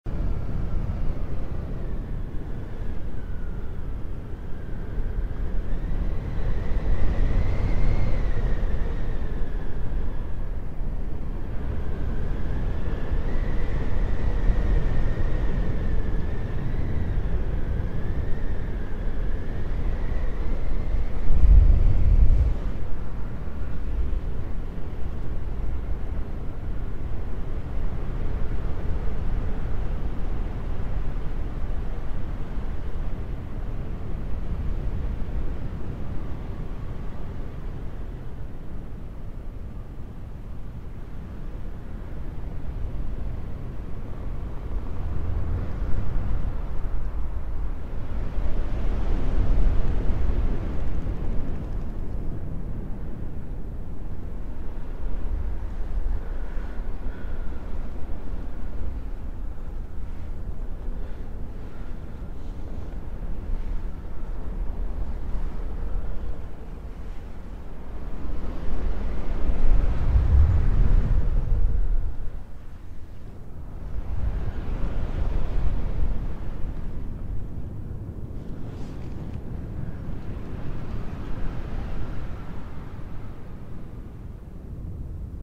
Howling Wind Ambience